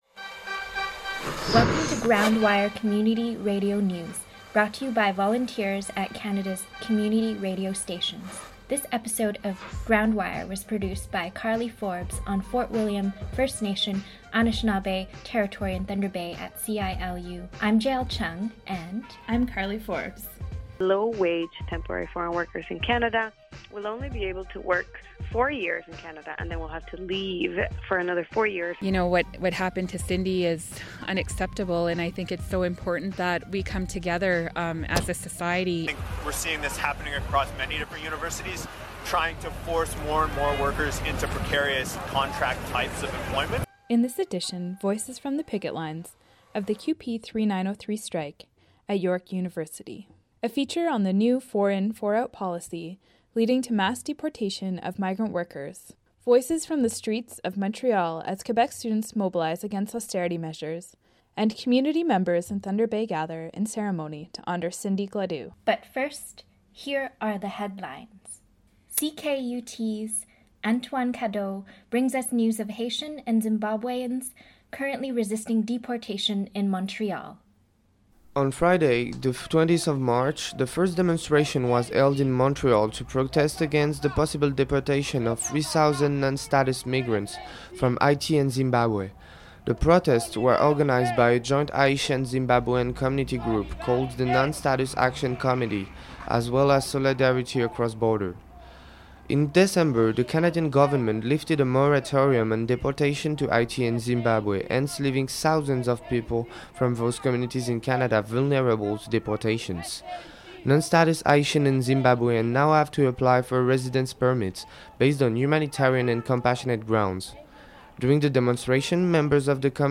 GroundWire Community Radio News